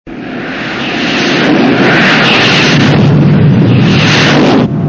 BSG FX - Viper Engine 03 Fly by
BSG_FX-Viper_Engine_03_Fly_By.mp3